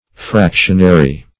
Fractionary \Frac"tion*a*ry\, a.